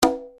Percs
div_perc1.wav